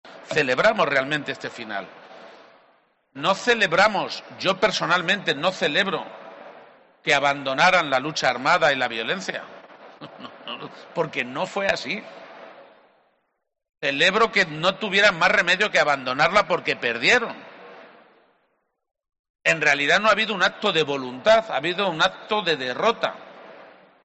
Declaraciones del presidente de Castilla-La Mancha, Emiliano García-Page, durante un acto público desde el Salón Gourmet que se celebra en el recinto ferial IFEMA de Madrid